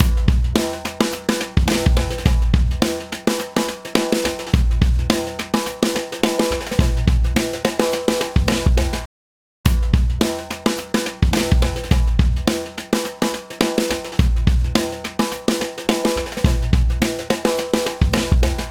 Предлагаю еще небольшой тест двух SSL.
Настройки те же. 10:1, 10 атака, 100 релиз, около 5 дб компрессии. Вложения Test SSL Bus.wav Test SSL Bus.wav 13,7 MB · Просмотры: 168